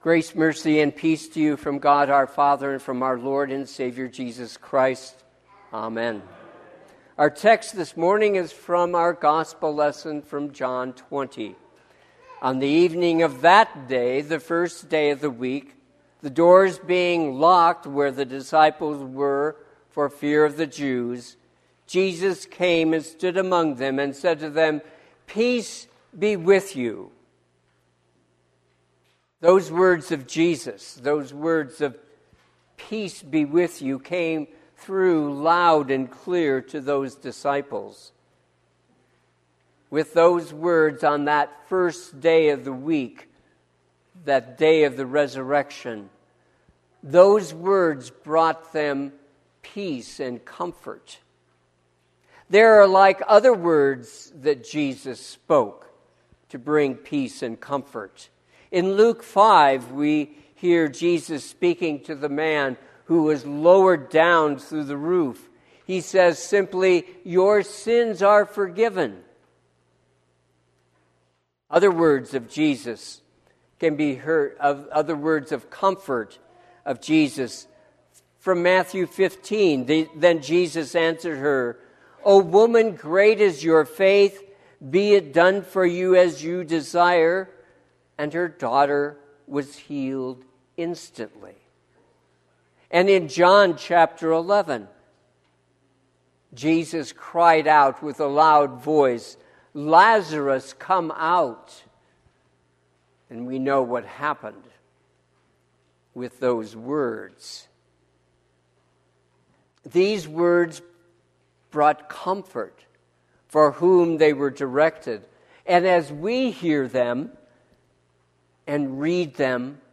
Sermon – 4/12/2026 - Wheat Ridge Evangelical Lutheran Church, Wheat Ridge, Colorado